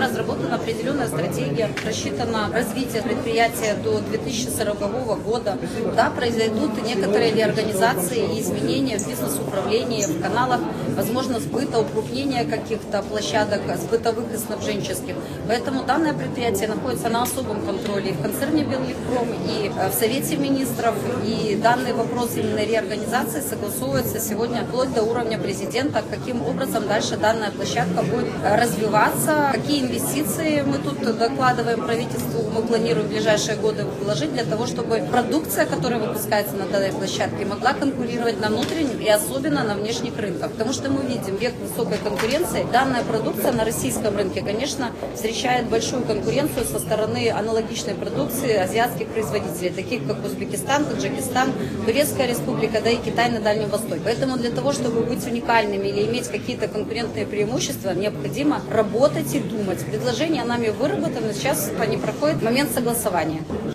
О стратегии развития Барановичского производственного хлопчатобумажного объединения, для чего вкладываются инвестиции и о том, как стать конкурентоспособными, — рассказала председатель концерна «Беллегпром» Татьяна Лугина.